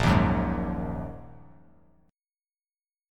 A#7sus4#5 chord